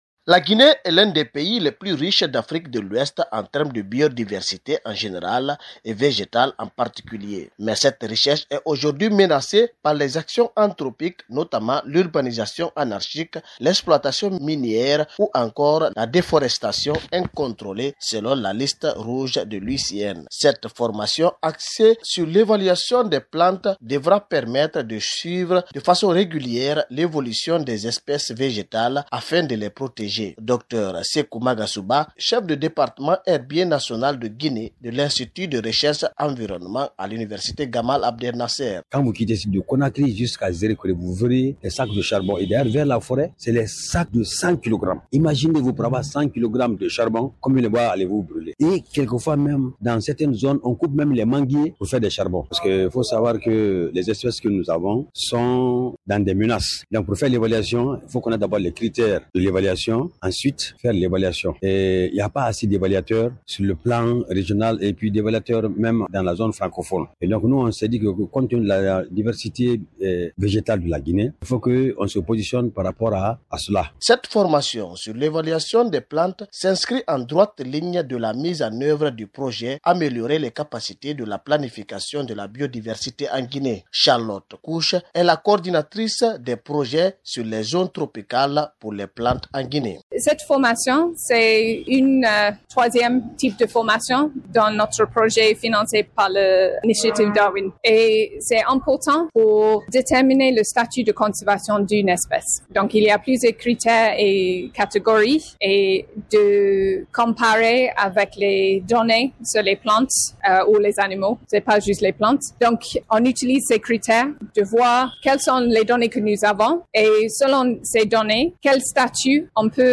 son reportage